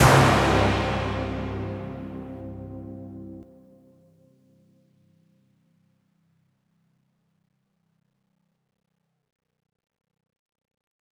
Hit (5).wav